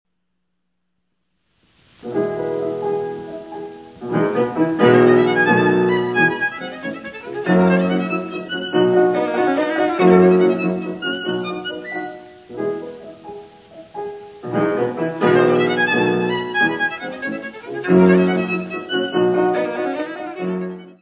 : mono; 12 cm
Music Category/Genre:  Classical Music
Scherzo: Presto.